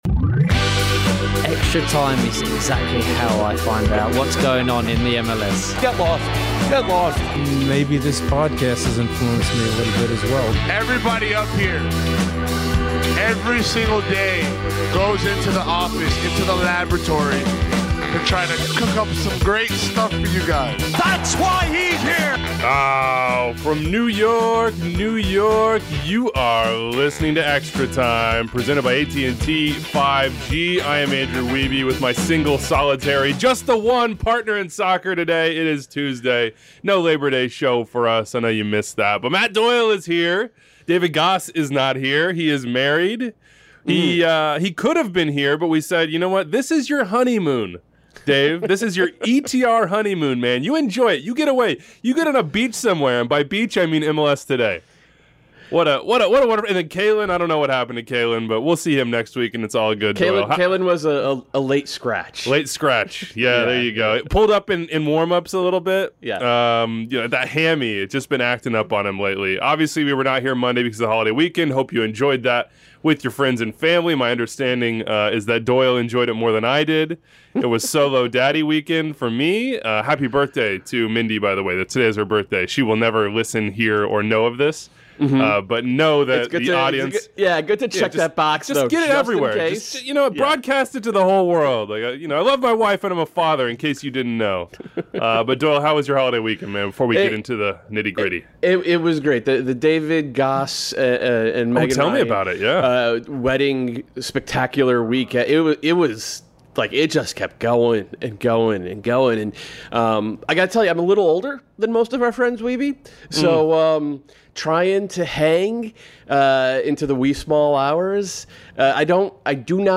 Oscar Pareja Interview
Todd Dunivant Interview